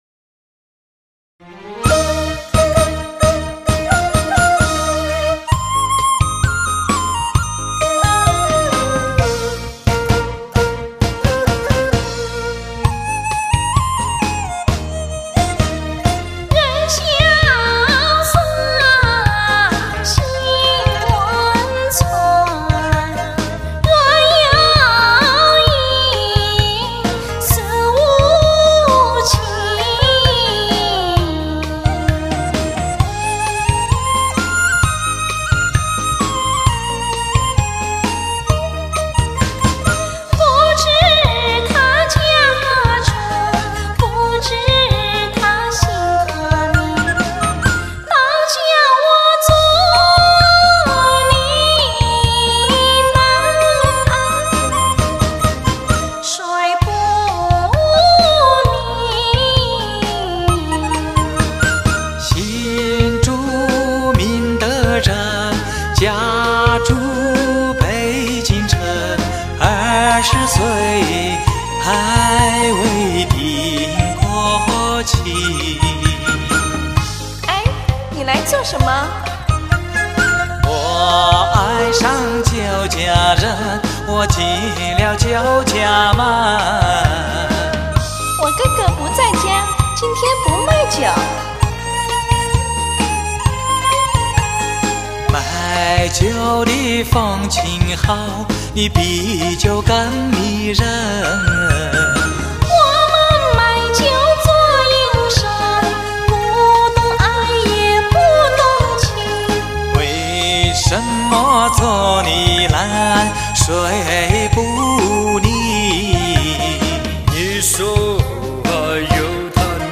重温经典恋曲 句句优美 声声入耳
音乐响起，优美的旋律，熟悉的音符，声声入耳；